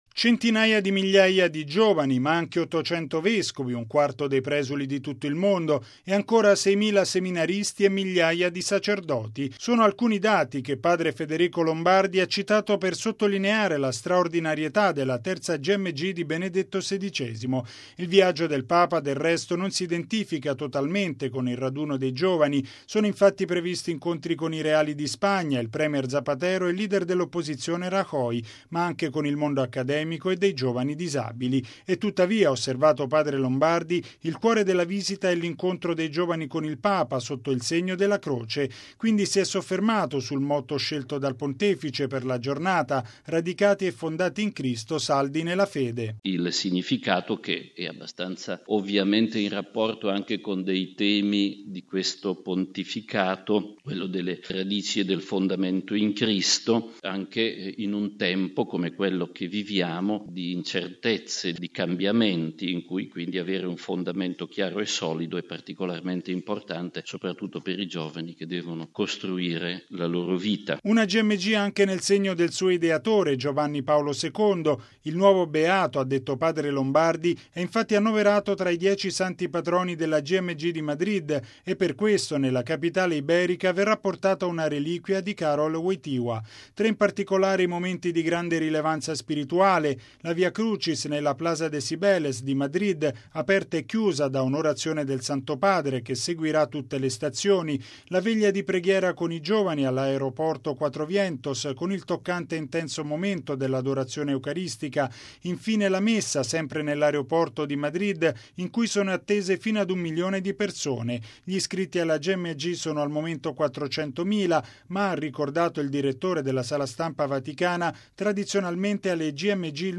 ◊   Il direttore della Sala Stampa della Santa Sede, padre Federico Lombardi ha tenuto stamani un briefing per illustrare il programma del Viaggio Apostolico del Papa a Madrid, in occasione della XXVI Giornata Mondiale della Gioventù. Si tratta del 20.mo viaggio internazionale di Benedetto XVI, il terzo in terra spagnola, ma il primo nella capitale Madrid.